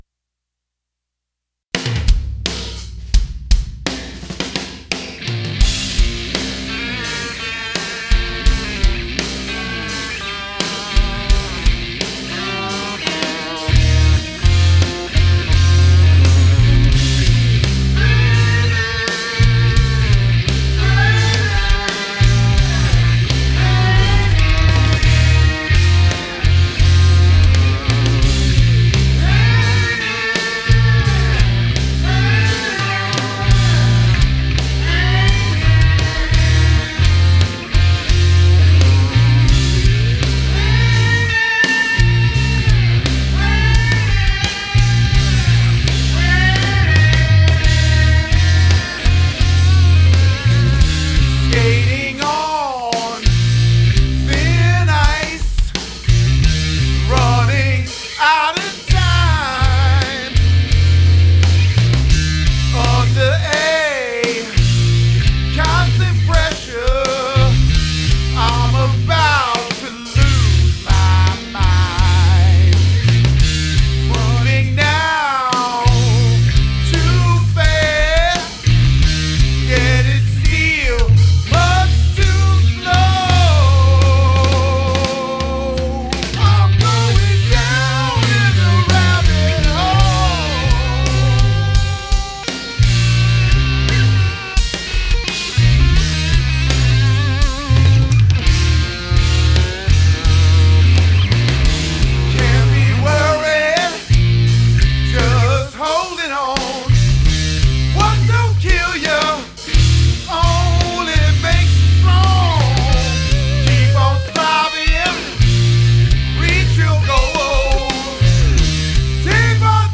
Blues , Electric Guitar
Rock